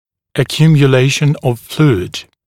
[əˌkjuːmjə’leɪʃn əv ‘fluːɪd][эˌкйу:мйэ’лэйшн ов ‘флу:ид]скопление жидкости